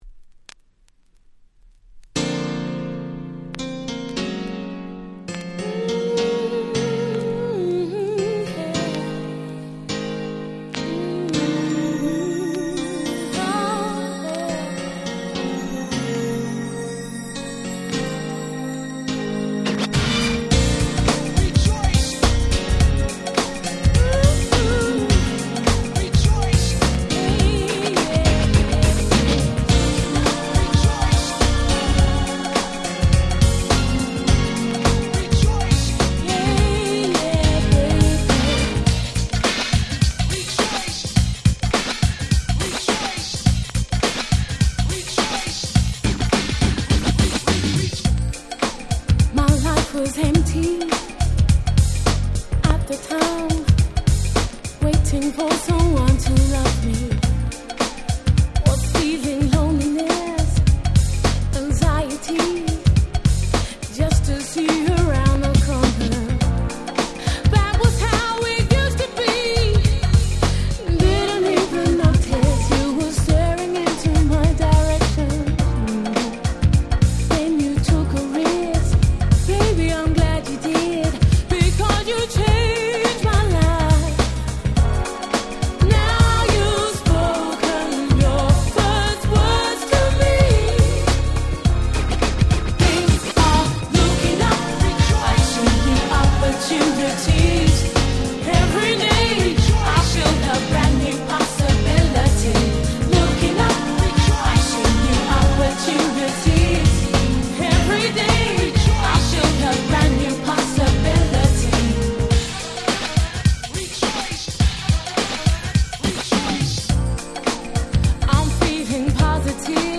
UK Promo Only Remix !!